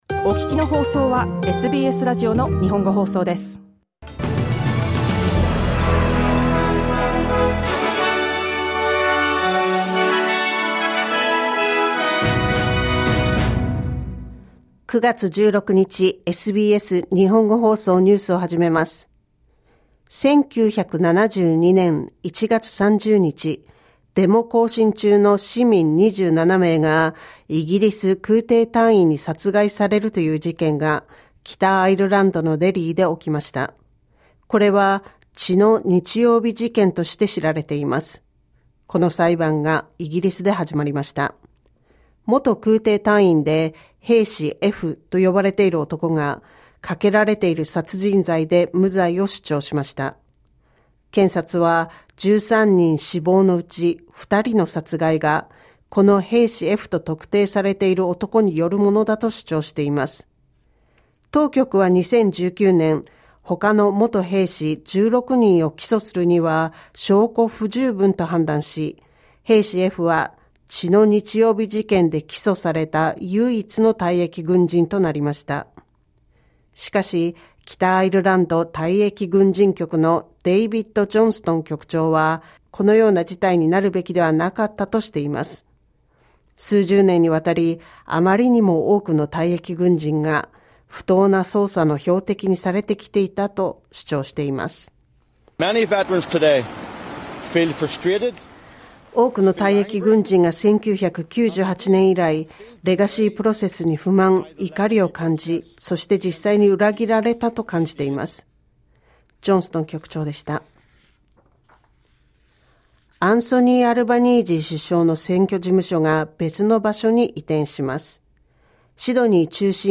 SBS日本語放送ニュース9月16日火曜日